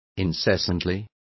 Complete with pronunciation of the translation of incessantly.